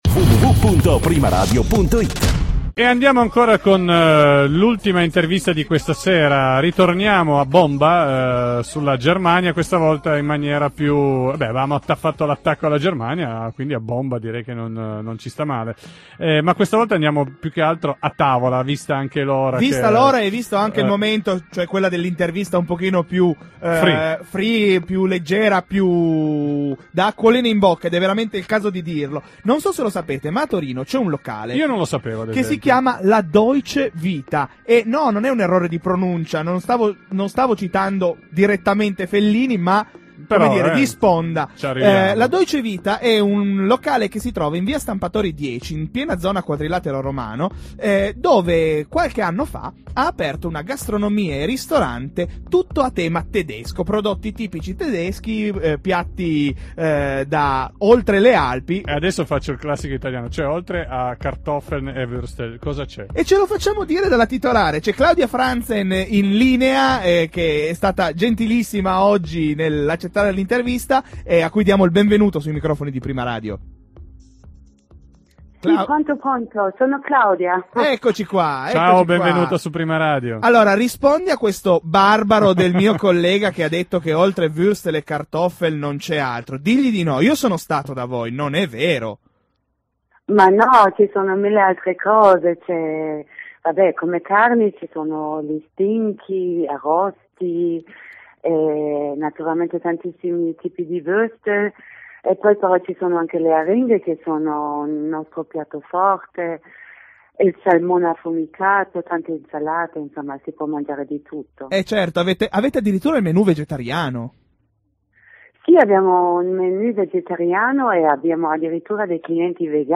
Intervista Prima Radio